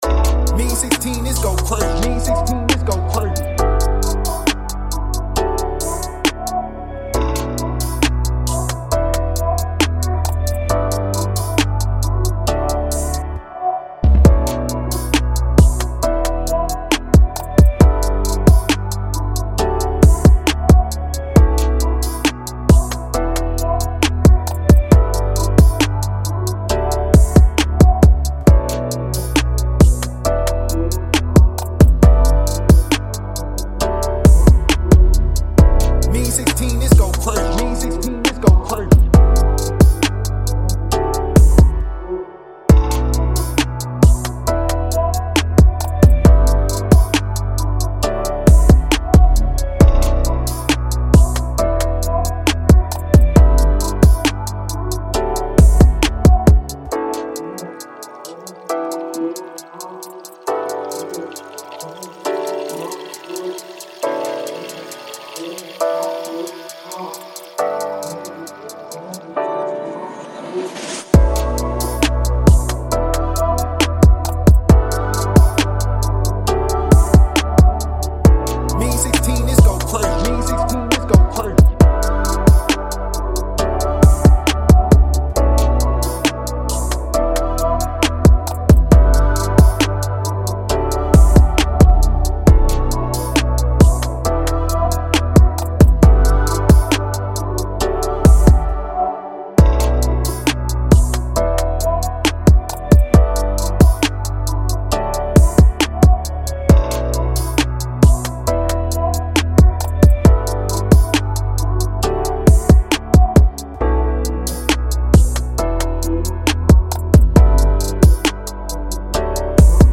Bb-Min 135-BPM